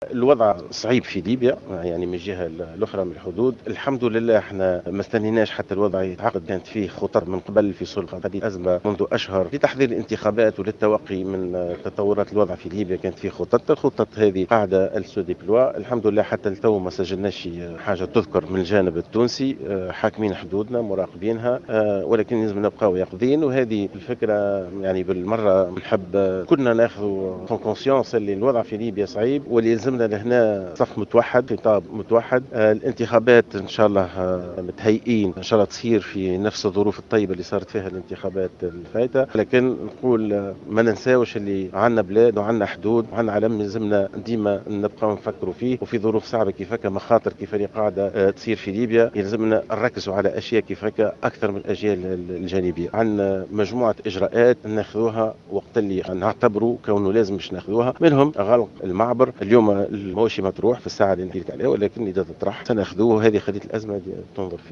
شدد رئيس الحكومة المؤقتة مهدي جمعة، في تصريح إعلامي اليوم الاثنين، على ضرورة تعزيز اليقظة والاحتياط على الحدود التونسية الليبية في ظل الصعوبات الأمنية التي يمر بها القطر الليبي.